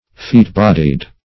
\Feat"-bod`ied\
feat-bodied.mp3